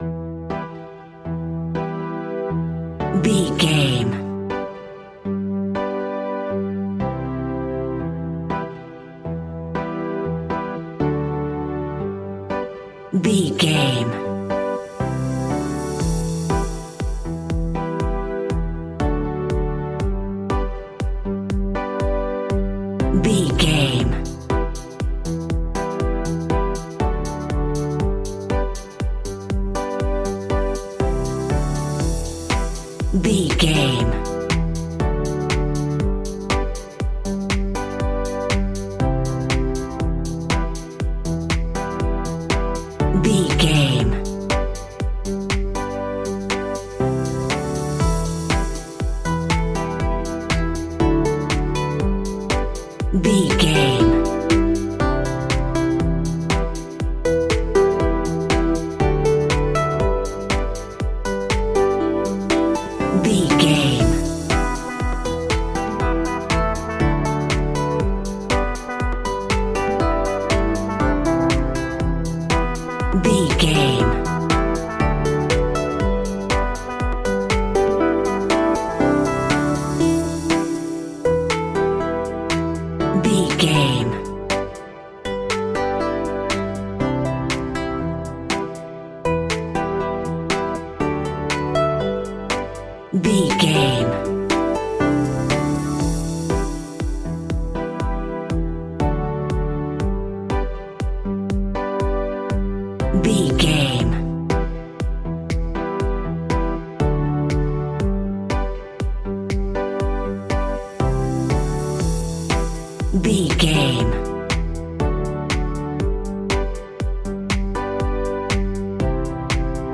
Aeolian/Minor
joyful
hopeful
synthesiser
drum machine
electric piano
acoustic guitar
electronic music
electronic instrumentals
synth leads
synth bass